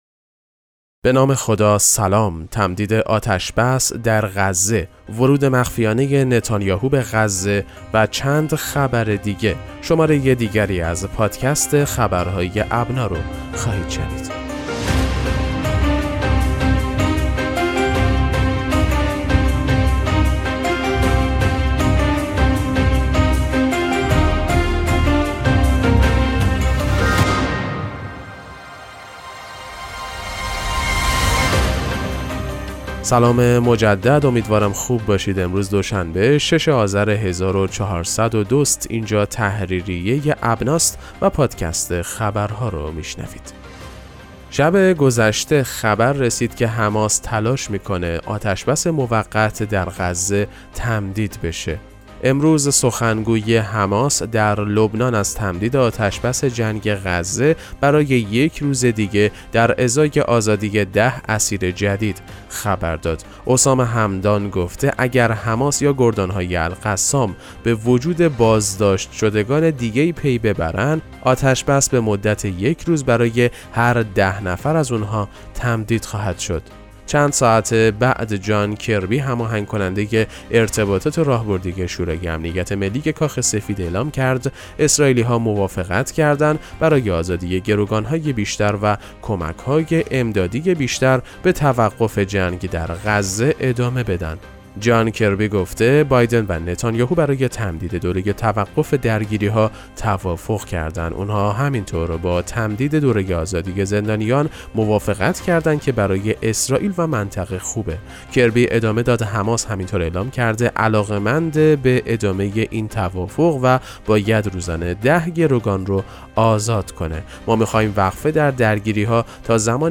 پادکست مهم‌ترین اخبار ابنا فارسی ــ 6 آذر 1402